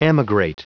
Prononciation du mot emigrate en anglais (fichier audio)
Prononciation du mot : emigrate
emigrate.wav